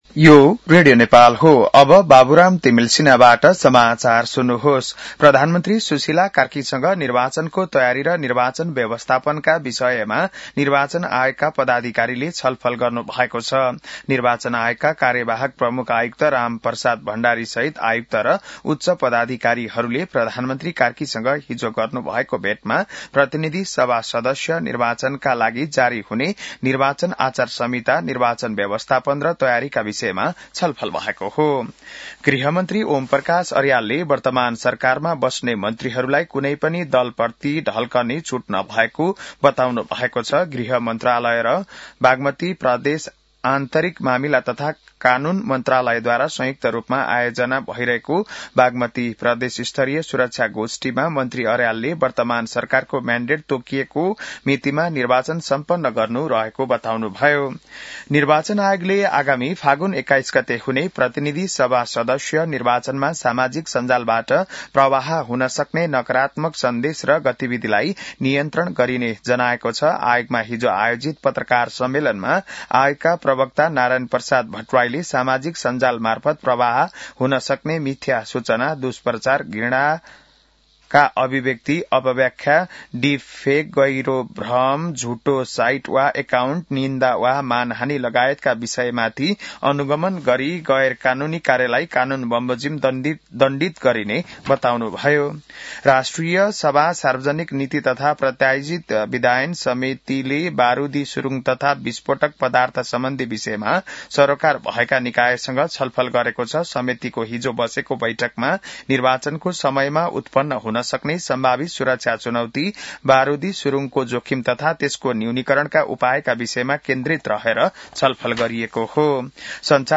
बिहान १० बजेको नेपाली समाचार